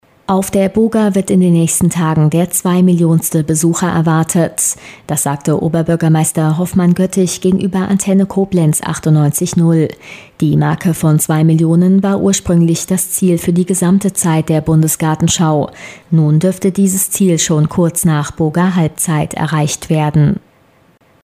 Antenne Koblenz 98,0, Nachrichten 29.07.2011
News-Meldung-2-Mio-erwartet.mp3